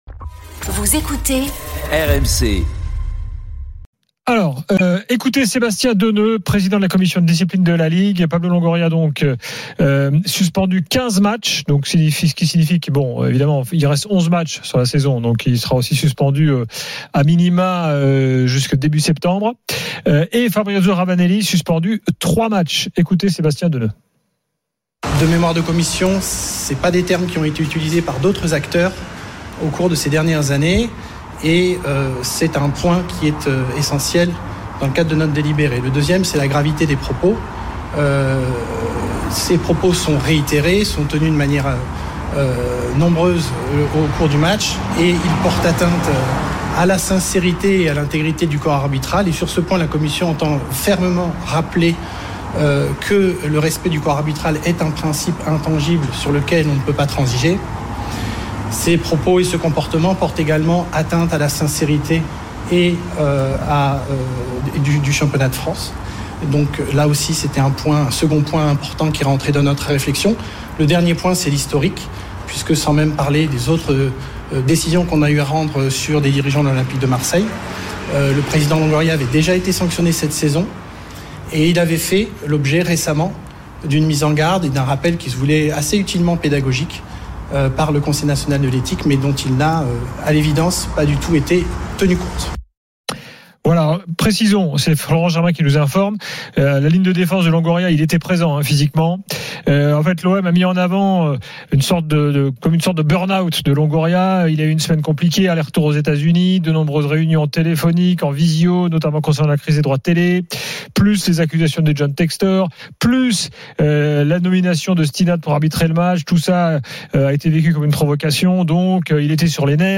Chaque jour, écoutez le Best-of de l'Afterfoot, sur RMC la radio du Sport !